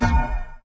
poly_explosion_holy.wav